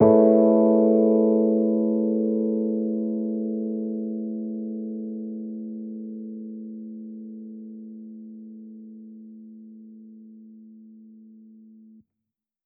Index of /musicradar/jazz-keys-samples/Chord Hits/Electric Piano 2
JK_ElPiano2_Chord-Amaj9.wav